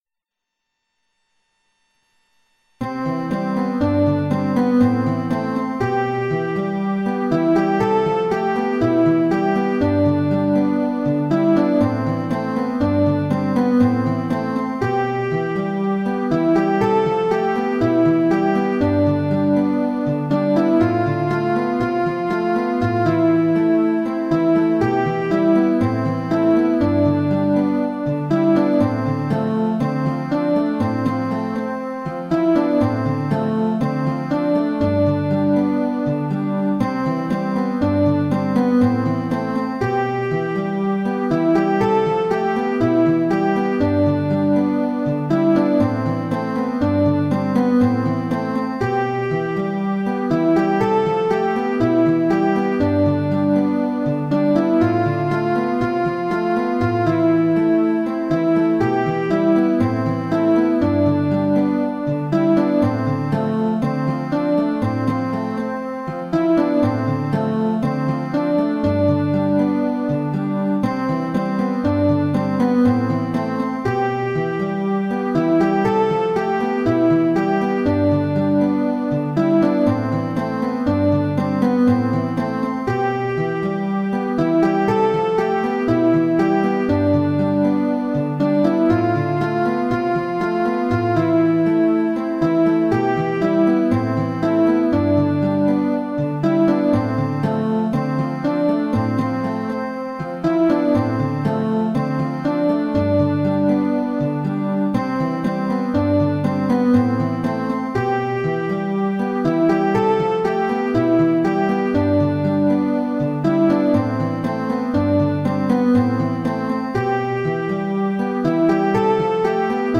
Psalm 146. Praise the Lord My Soul. A melodic and graceful praise to our faithful God.